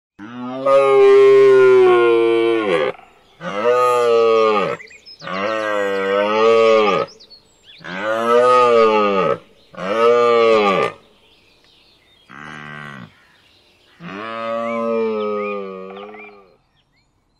Hirsch
Hirsch-fertig.mp3